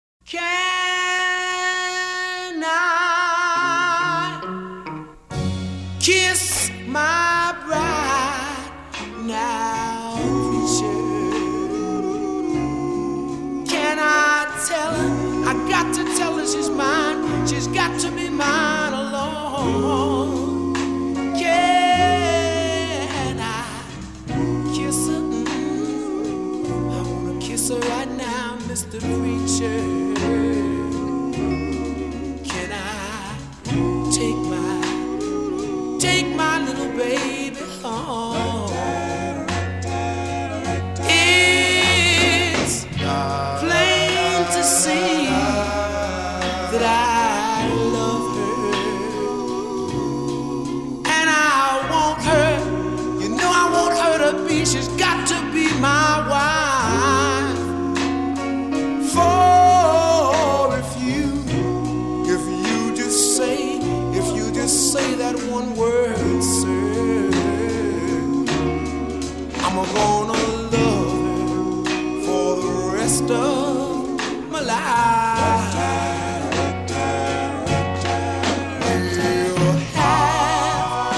(R&B/Soul)